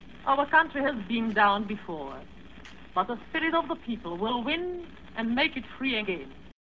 I Saw My Country Die: A Radio Interview with Jarmila Novotna